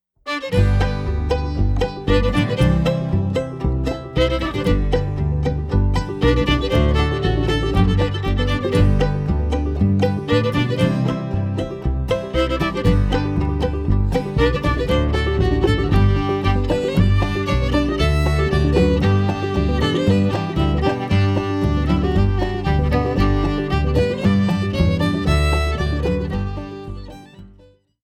Old Time Music of SW Pennsylvania
fiddle
banjo, fife, accordion
guitar
upright bass Between 1928 and 1963